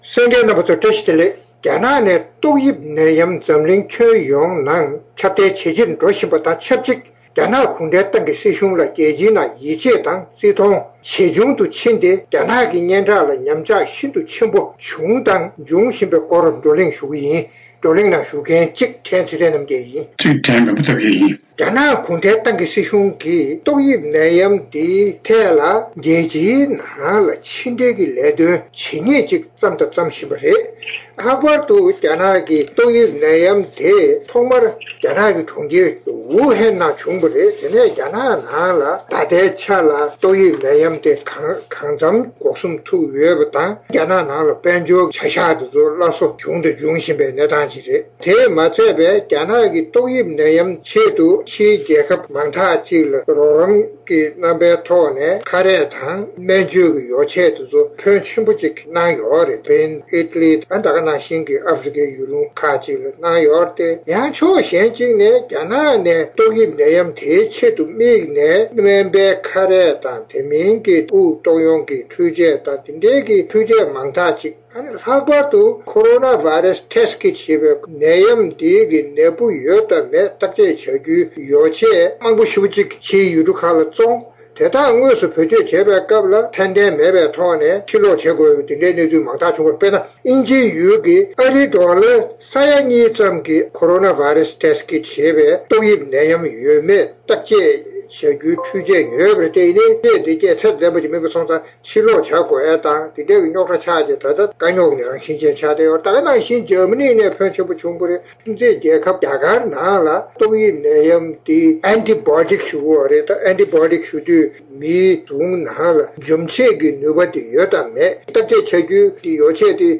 རྩོམ་སྒྲིག་པའི་གླེང་སྟེགས་ཞེས་པའི་ལེ་ནང་། རྒྱ་ནག་ནས་ཏོག་དབྱིབས་ནད་ཡམས་འཛམ་གླིང་ཁྱོན་ཡོངས་ནང་ཁྱབ་གདལ་ཇེ་ཆེར་འགྲོ་བཞིན་པ་དང་ཆབས་ཅིག རྒྱ་ནག་གུང་གྲན་ཏང་གི་སྲིད་གཞུང་ལ་རྒྱལ་སྤྱིའི་ནང་ཡིད་ཆེས་དང་། བརྩི་མཐོང་ཇེ་ཆུང་དུ་ཕྱིན་ཏེ་རྒྱ་ནག་གི་སྙན་གྲགས་ལ་ཉམས་ཆགས་ཤིན་ཏུ་ཆེན་པོ་བྱུང་དང་འབྱུང་བཞིན་པའི་སྐོར་གླེང་མོལ་གནང་བ་ཞིག་གསན་རོགས་གནང་།།